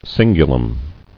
[cin·gu·lum]